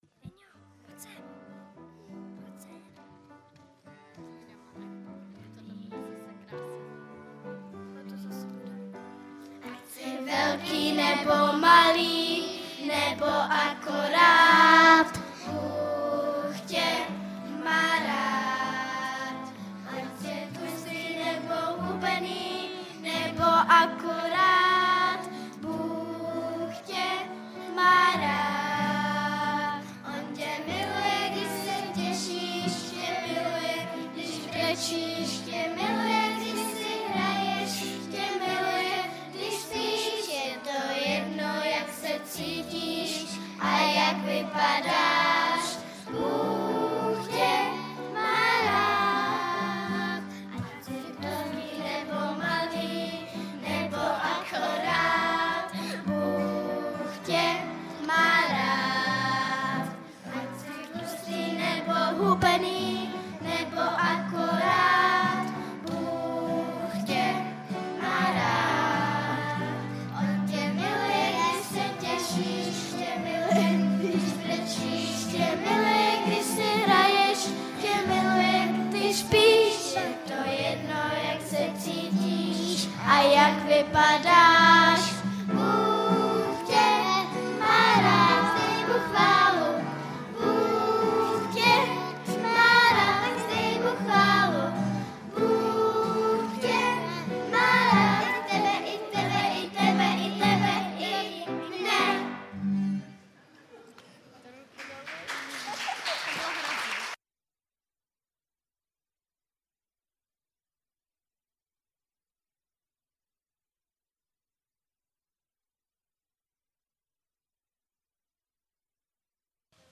Rodinná bohoslužba
Twitter Digg Facebook Delicious StumbleUpon Google Bookmarks LinkedIn Yahoo Bookmarks Technorati Favorites Tento příspěvek napsal admin , 19.2.2017 v 11:03 do rubriky Kázání .